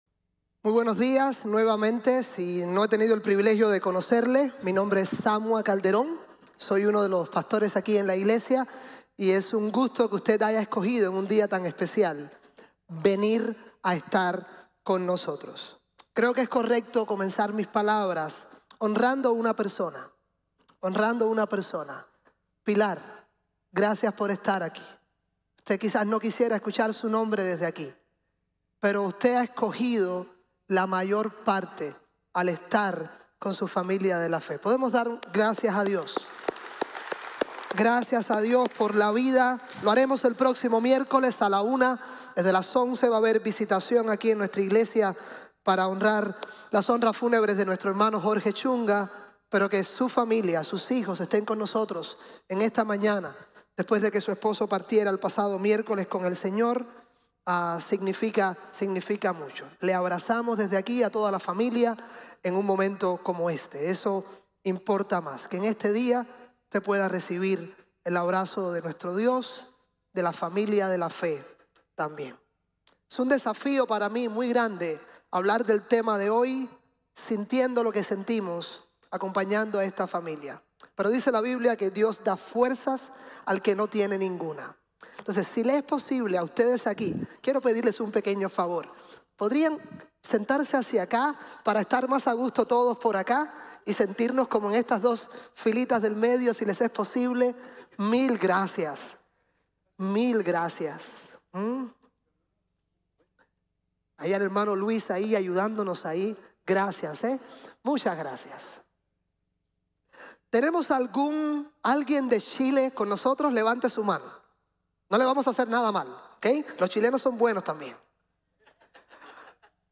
Vida, aún en el desierto | Sermon | Grace Bible Church